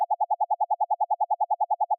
Blipping.wav